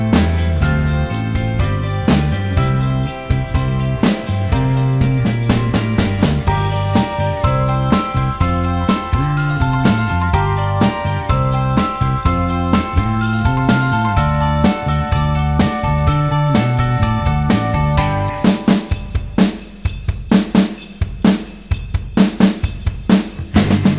Intro Cut And No Backing Vocals Rock 3:44 Buy £1.50